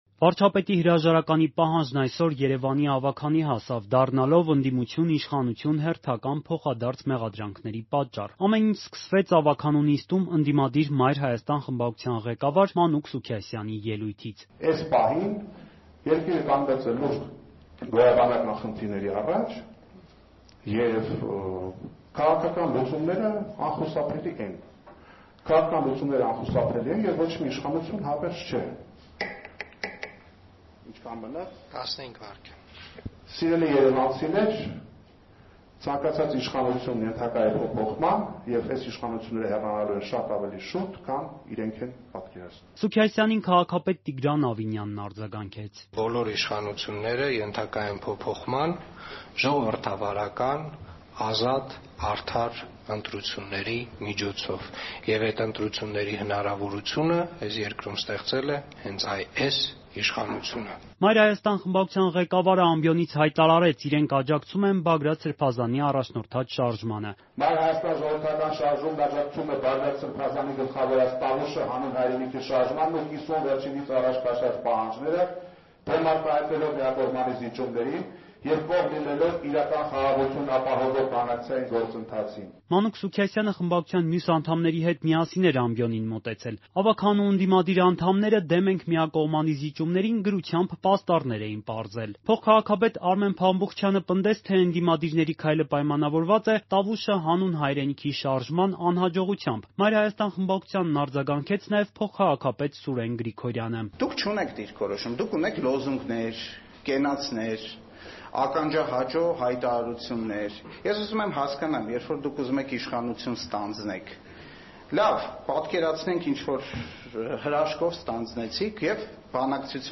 «Մայր Հայաստան» խմբակցության անդամները Երևանի ավագանու նիստին, 14-ը մայիսի, 2024թ.
Մանրամասները՝ «Ազատության» ռեպորտաժում.